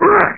ko-hit02.mp3